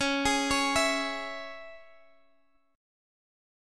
Listen to C#m strummed